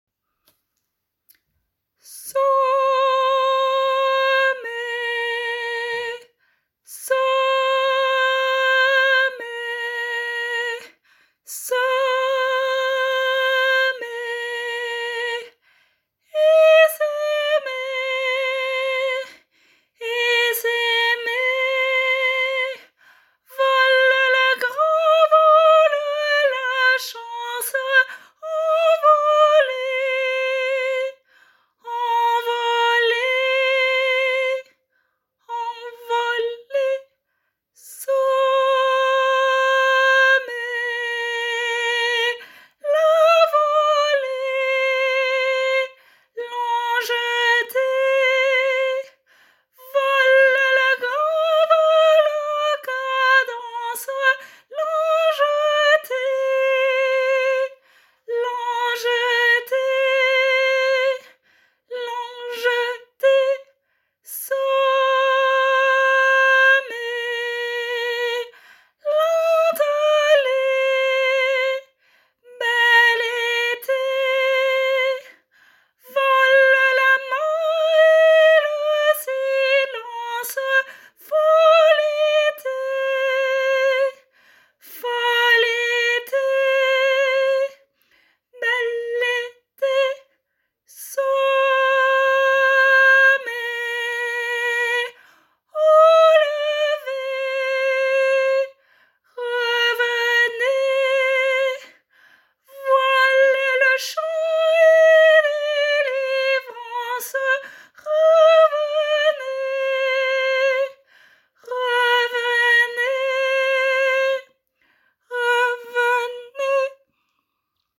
Semailles soprano